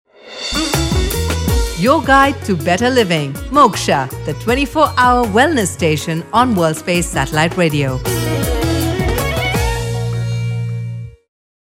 britisch
Sprechprobe: Industrie (Muttersprache):
female voice over talent english (uk).